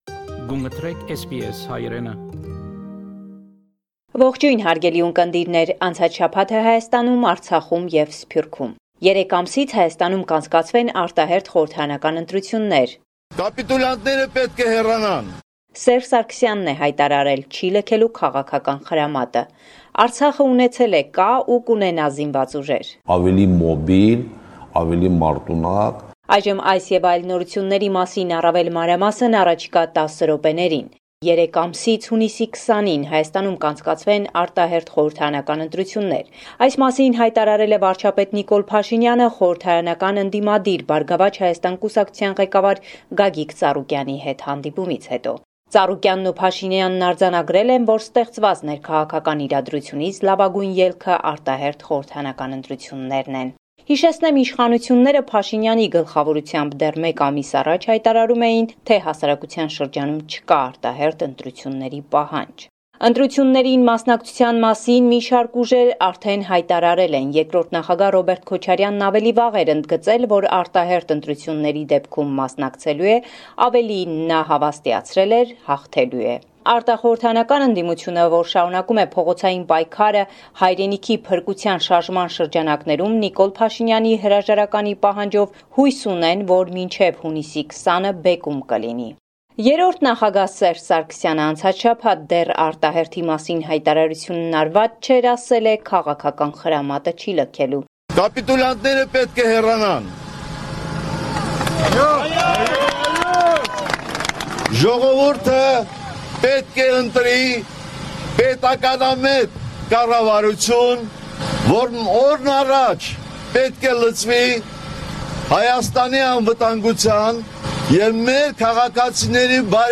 Latest News from Armenia – 23 March 2021